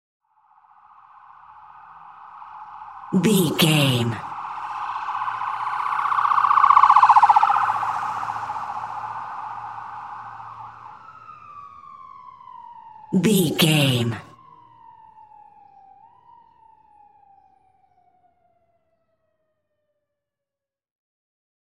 Ambulance Ext Passby Stress Large Siren
Sound Effects
urban
chaotic
anxious
emergency